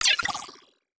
Space UI Touch and Reaction 2.wav